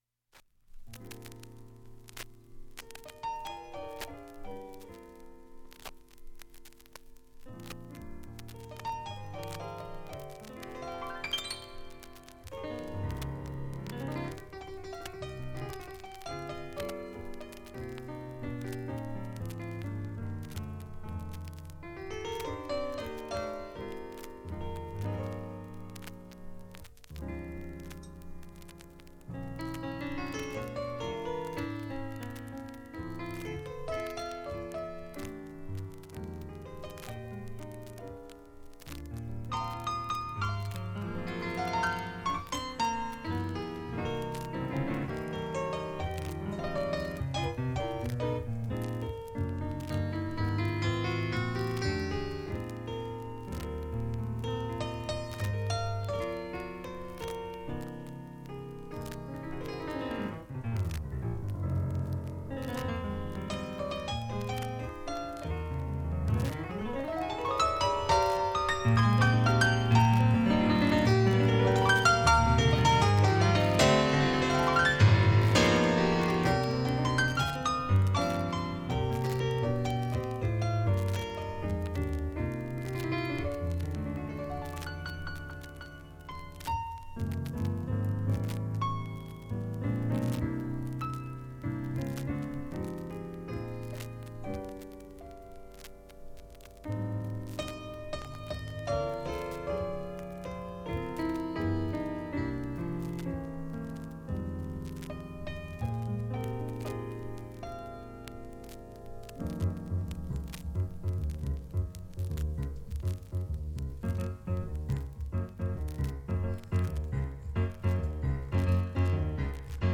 盤面きれいで音質良好全曲試聴済み
B-1イントロピアノソロ２分ほどの間
周回サッという音出ますが